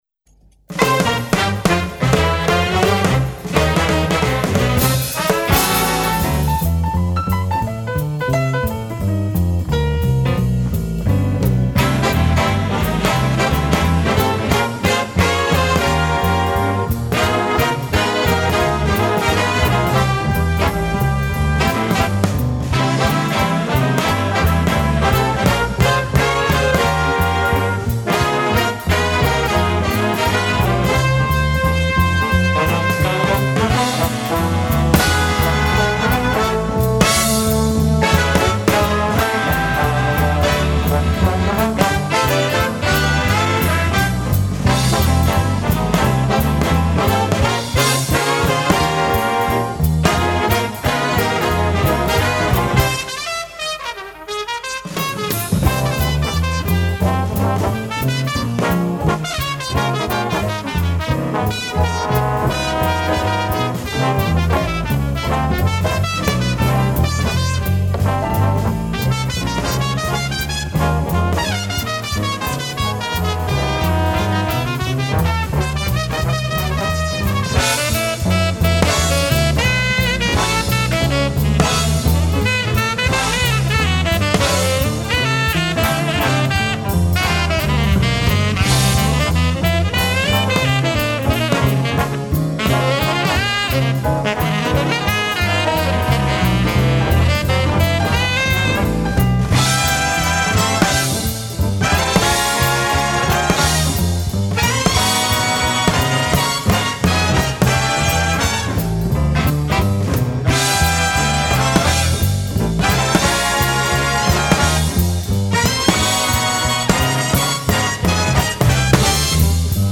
Big Band
Studioproduktion